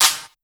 normal-hitclap-1.wav